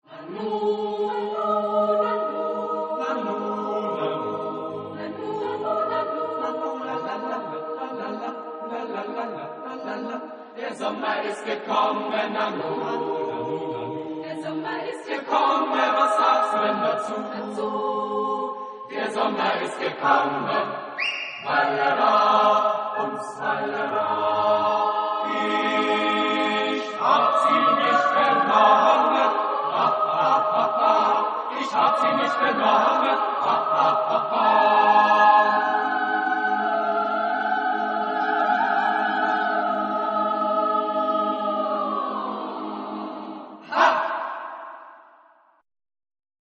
Genre-Style-Forme : Folklore ; Chanson ; Profane
Type de choeur : SSAATTBB  (8 voix mixtes )
Tonalité : sol majeur